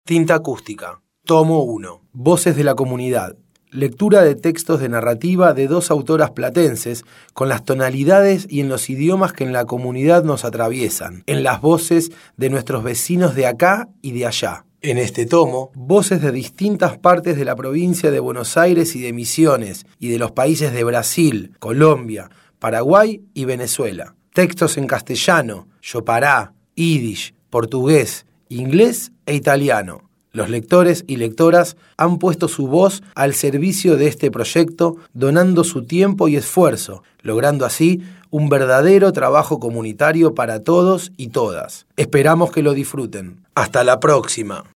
Palabras claves: Narración de cuentos ; Audiolibro